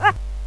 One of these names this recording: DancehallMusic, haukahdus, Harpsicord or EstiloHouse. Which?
haukahdus